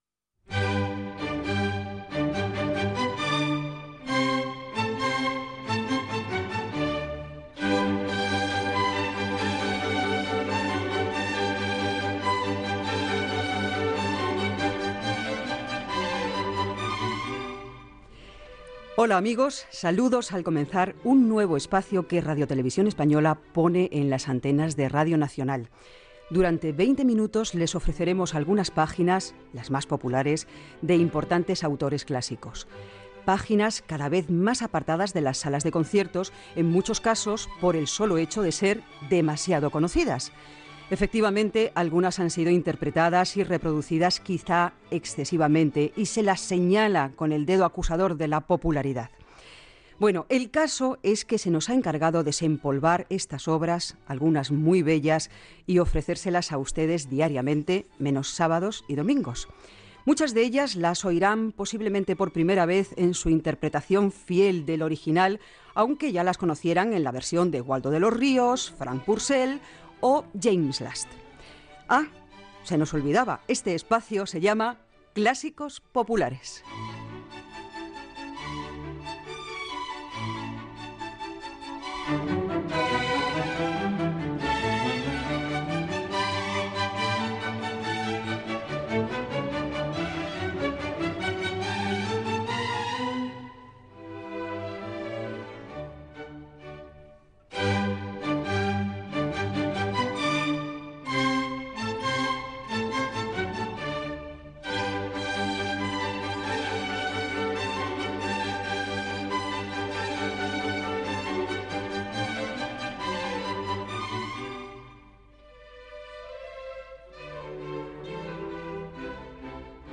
Repàs a la història del programa i als presentadors i tècnics. Entrevista telefònica
Gènere radiofònic Musical